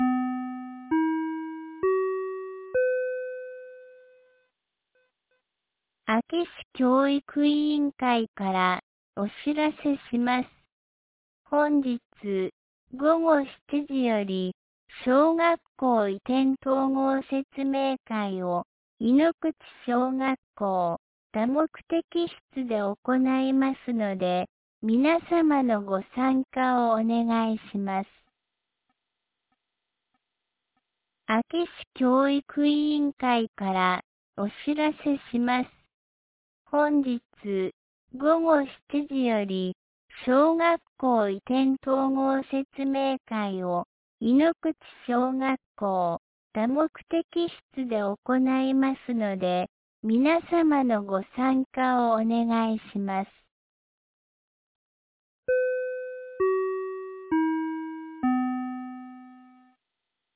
2026年01月28日 12時56分に、安芸市より井ノ口、小谷、栃ノ木、下尾川、安芸ノ川、畑山、舞川へ放送がありました。